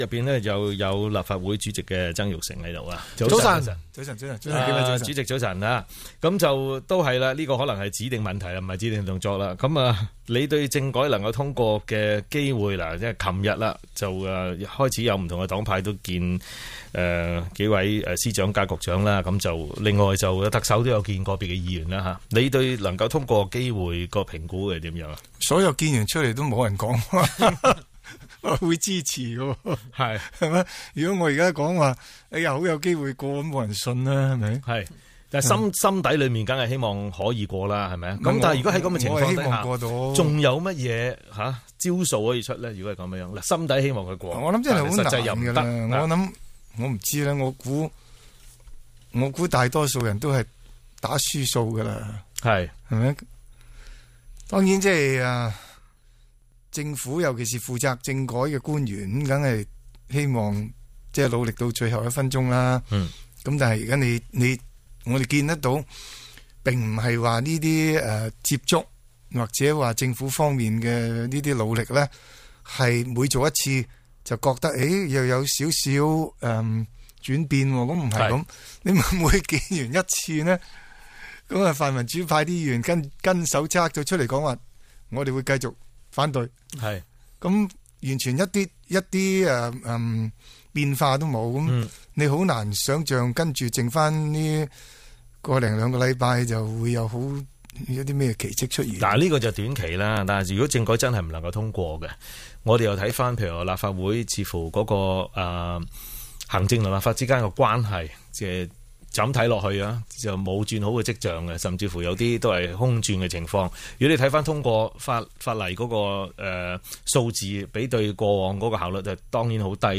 DBC數碼電台《早晨八達通》訪問